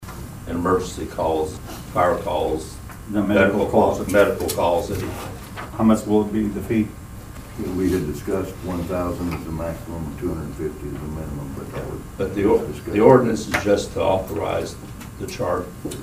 You heard City Attorney Terry McVey and Councilmen Steve Panousis and Bill Palmer.